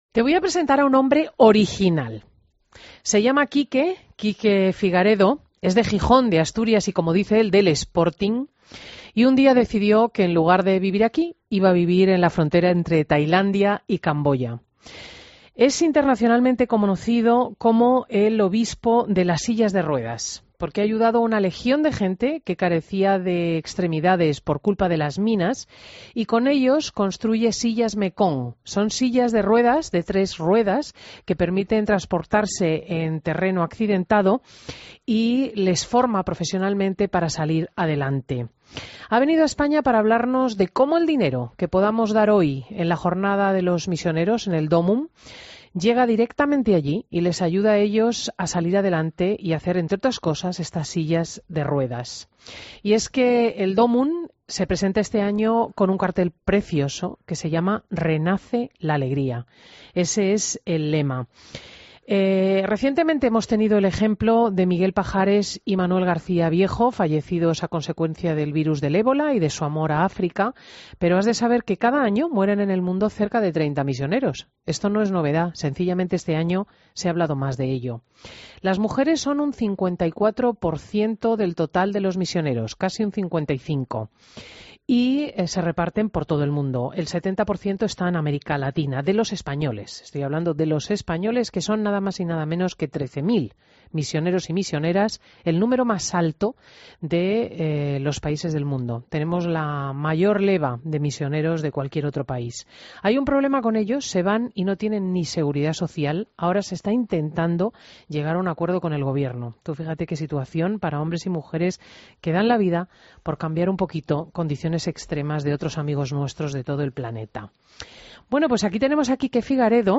AUDIO: Entrevista a Enrique Figaredo en Fin de Semana COPE